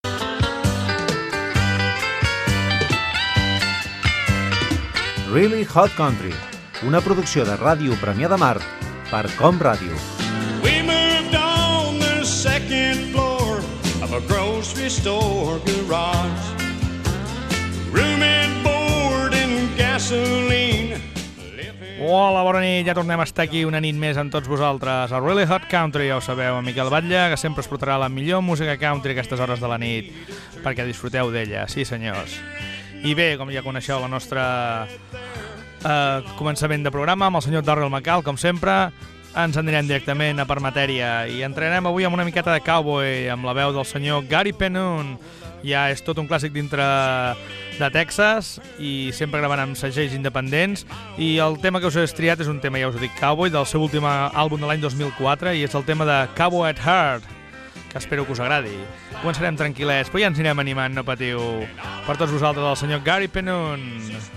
Careta, inici del programa i tema musical
Musical